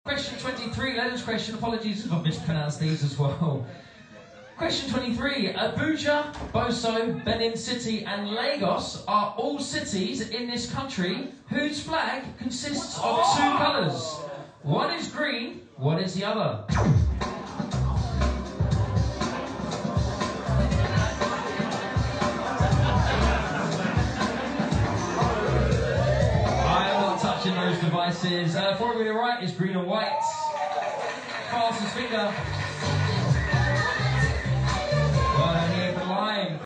A live stream at our quiz in Islington on the 20th May 2024 caught this curveball moment…